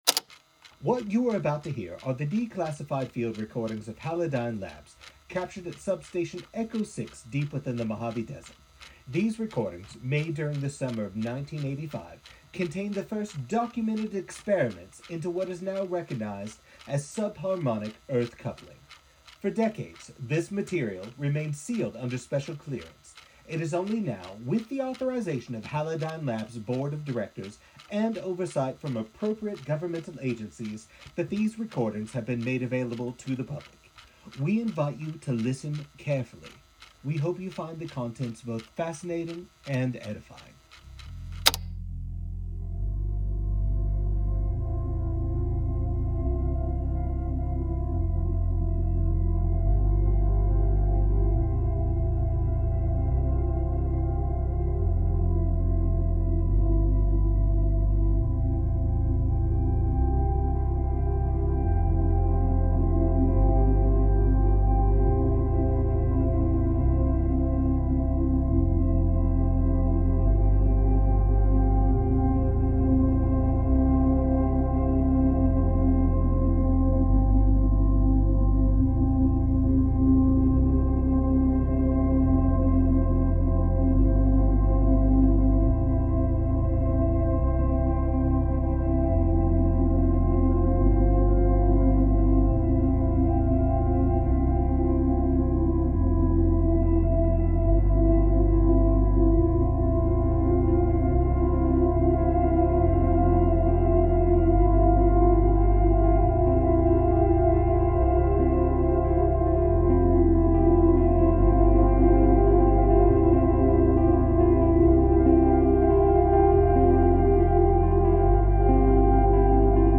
HALIDYNE LABS FIELD RECORDINGS: Mojave Substation Echo-6
Halidyne-LAbs-Field-Recording-1.mp3